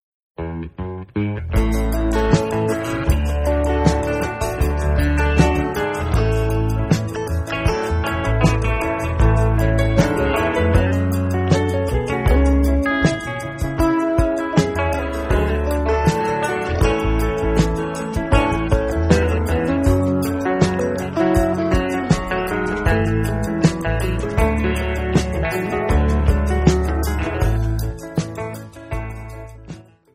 Jamband
Psychedelic
Rock